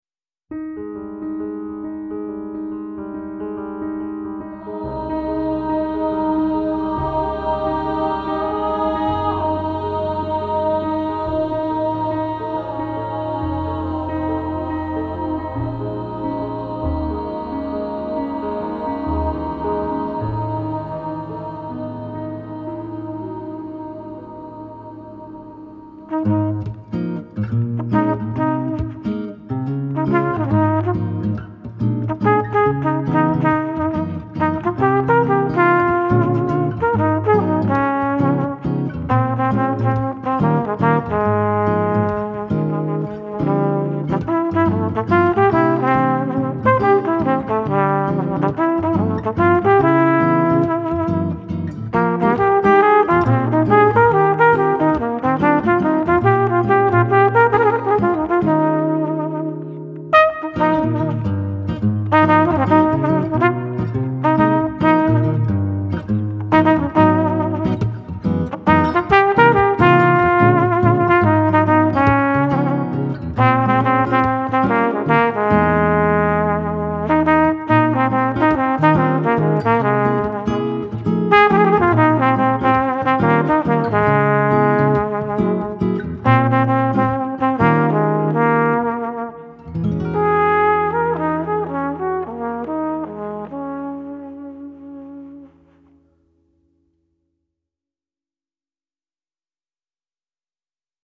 Genre : Jazz contemporain